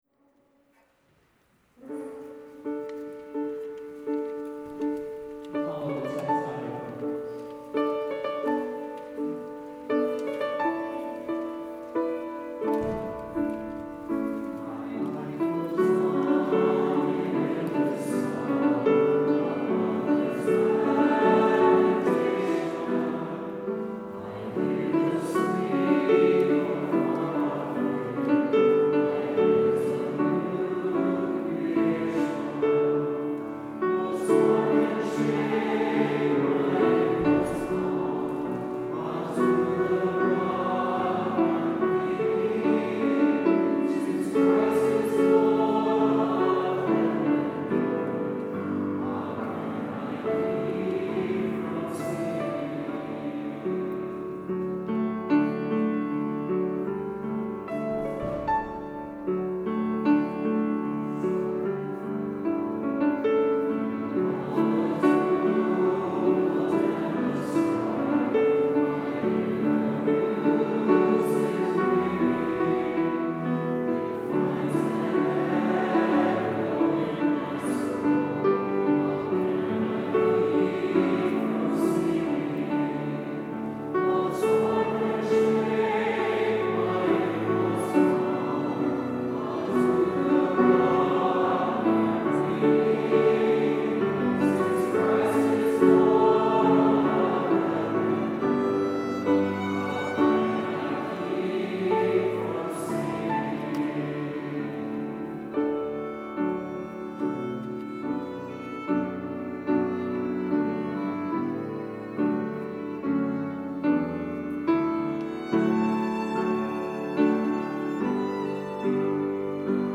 Closing Hymn                                                                           How Can I Keep from Singing?
(the recording below is from the “DITA at 10” conference, Duke University)